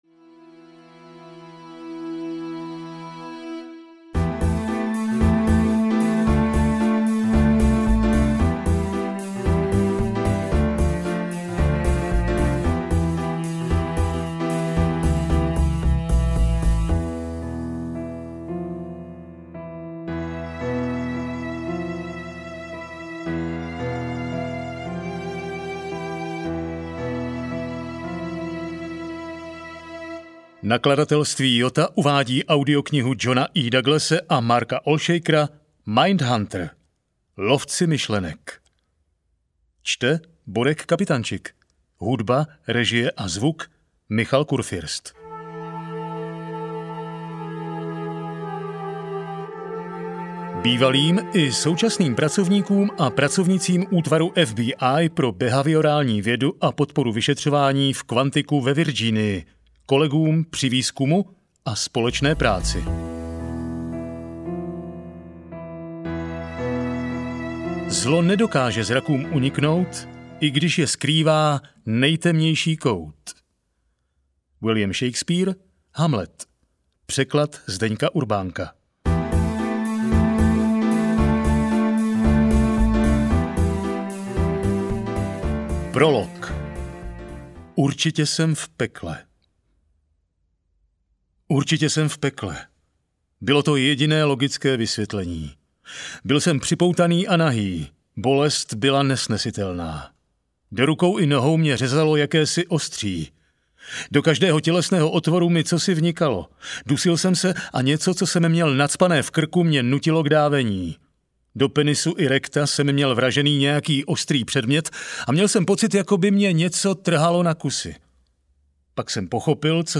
Audio knihaMindhunter – Lovci myšlenek
Ukázka z knihy